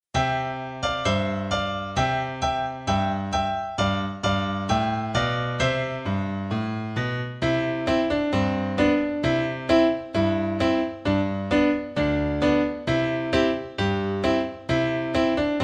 Slow and
Nursery Rhyme